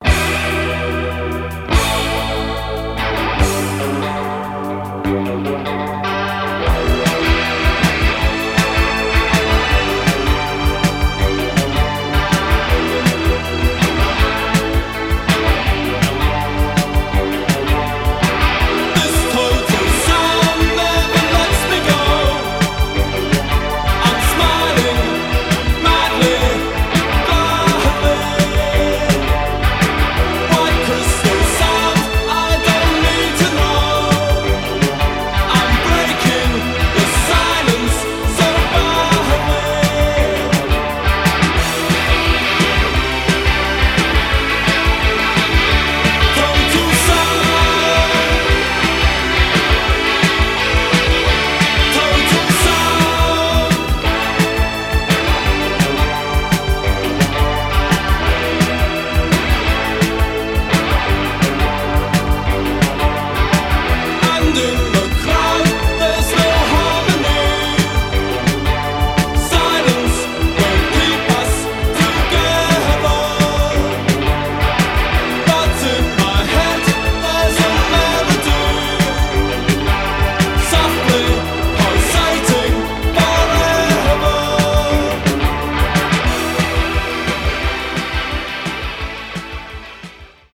post-punk